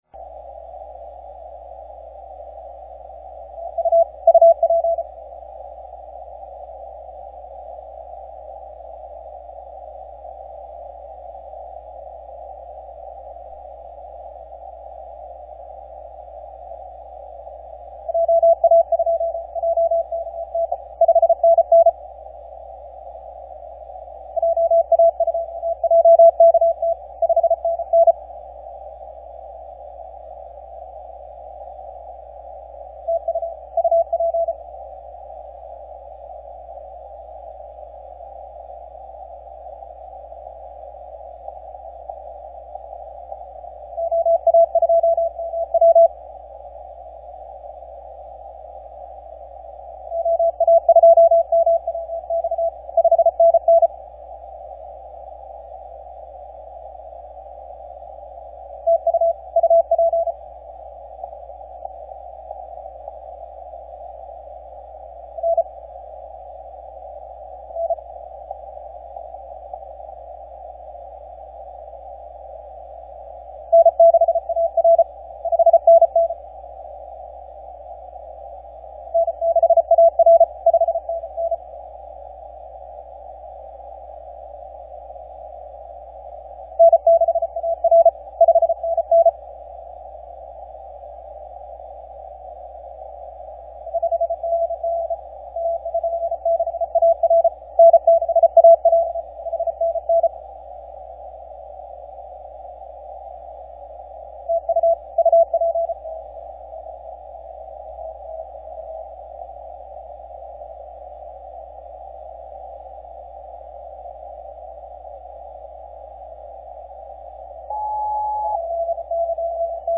MP3 recording of 3Y0K 17 Meters Longpath 1400Z, March 4, 2026.